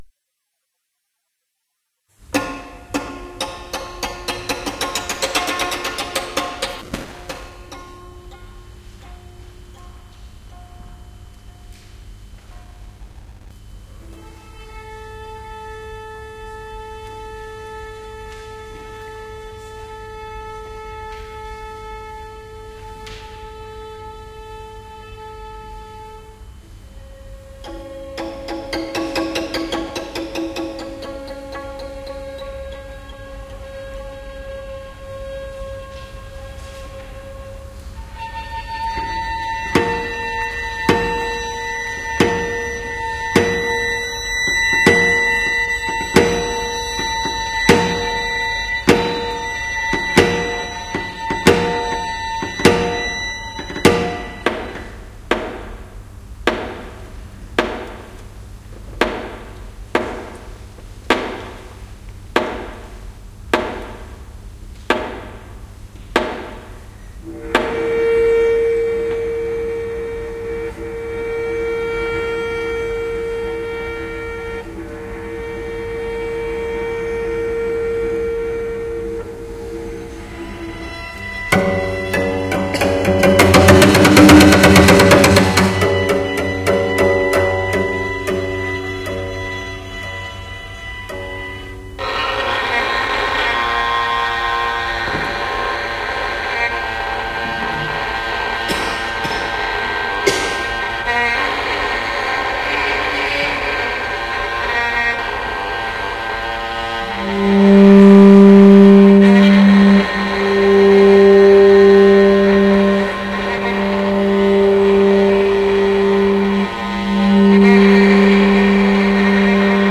I'm not so fond of the string quartet, but I bet it has much moredrama in person than online. I can imagine the dropped bows andpolyrhythms building tension. The bow dragging can be made into atheatre piece by the right performer.